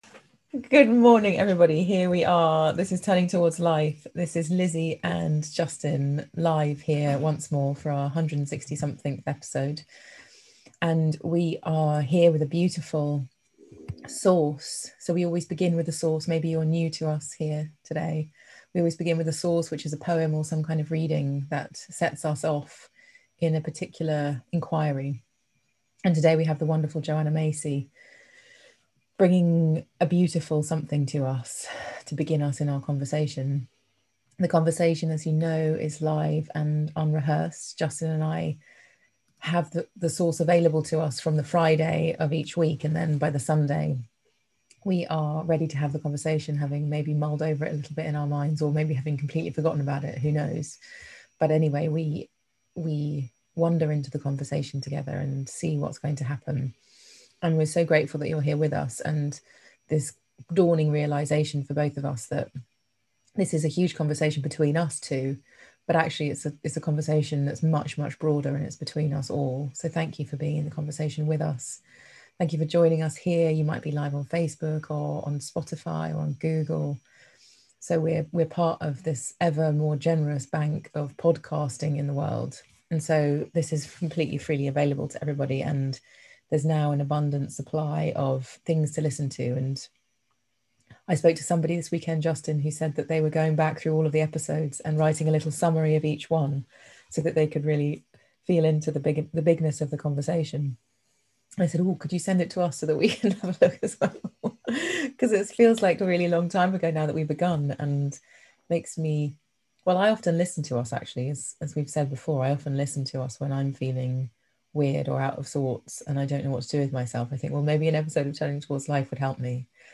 weekly live 30 minute conversation